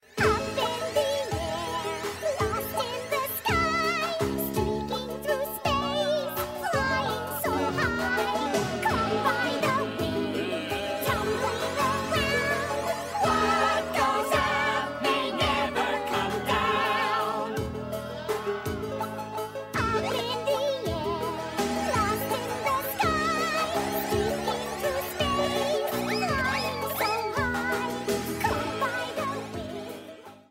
Song
This is a sample from a copyrighted musical recording.